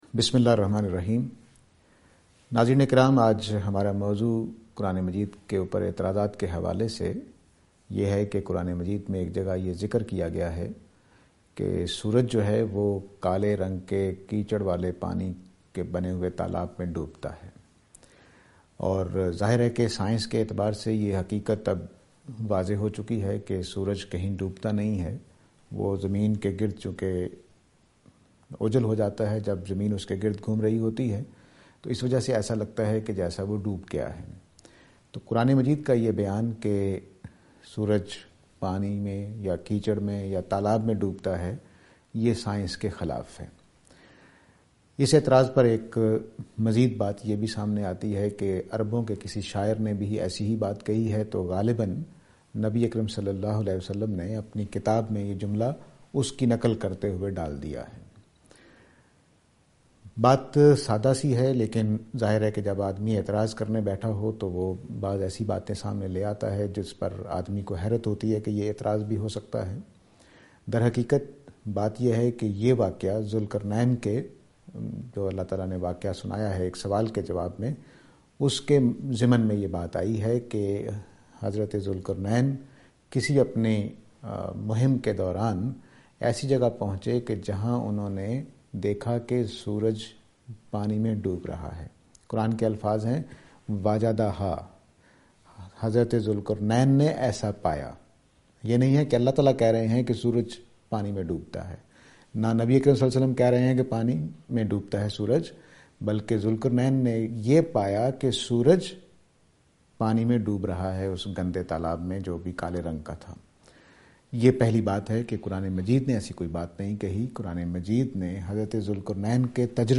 This lecture will present and answer to the allegation "Setting the sun in the mud".